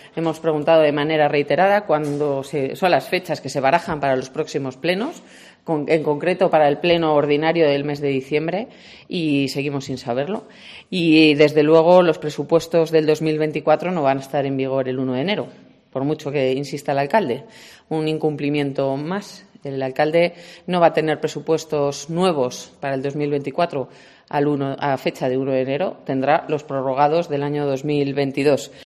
Clara Martín, portavoz socialista en el Ayuntamiento de Segovia, descarta presupuestos el 1 de enero